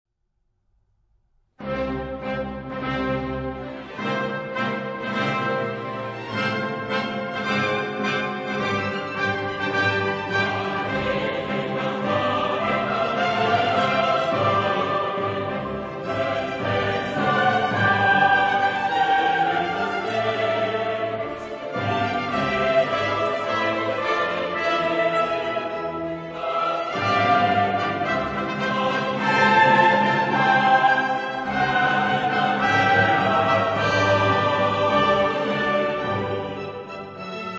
Genre-Style-Form: Sacred ; Romantic ; Hymn (sacred)
Mood of the piece: joyous ; majestic
Type of Choir: SATB  (4 mixed voices )
Soloist(s): Sopran (1) / Alt (1) / Tenor (1) / Bass (1)  (4 soloist(s))
Instrumentation: Orchestra  (13 instrumental part(s))
Instruments: Oboe (2) ; Bassoon (2) ; Trumpet (2) ; Timpani (1) ; Violin I ; Violin II ; Viola (1) ; Cello (1) ; Double bass (1) ; Organ (1)
Tonality: C major